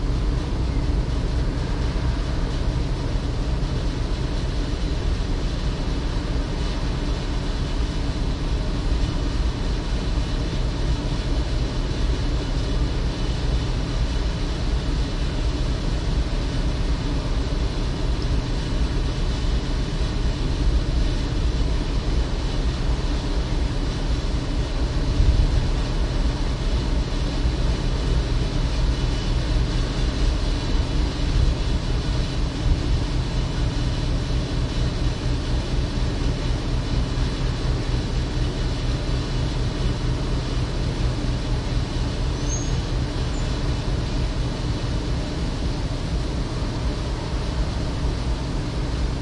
FERRY
描述：凌晨4点左右，在从贝尔法斯特前往利物浦伯肯黑德的途中，在一个空的渡轮甲板上进行录音。
标签： 场 - 记录 甲板 爱尔兰海域 渡轮
声道立体声